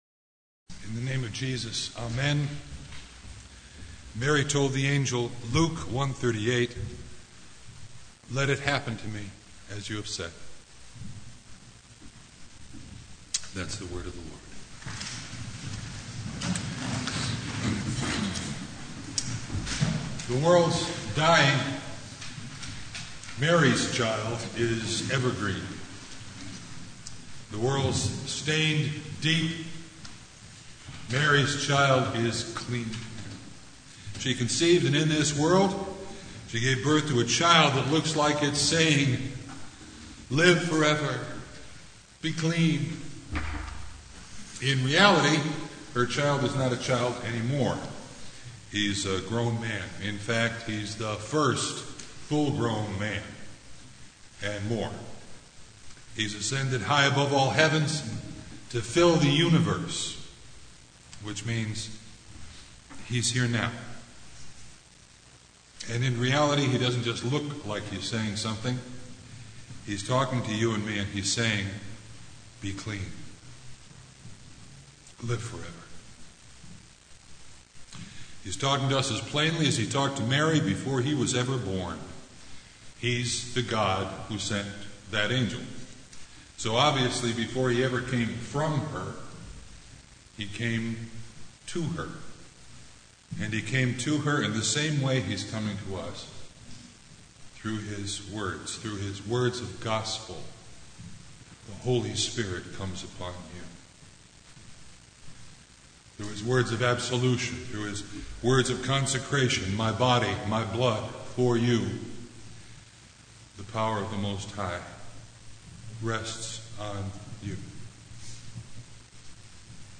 Passage: Luke 1:38 Service Type: Advent Vespers
Sermon Only